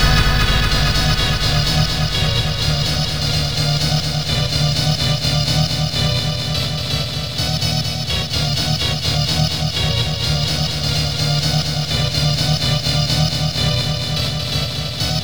• rave sequence black train 1 - D#m - 126.wav
Royalty free samples, freshly ripped from a rompler, containing sounds of the early rave and hardcore from the 90′s. These can represent a great boost to your techno/hard techno/dance production.
rave_sequence_black_train_1_-_D_sharp_m_-_126_1dn.wav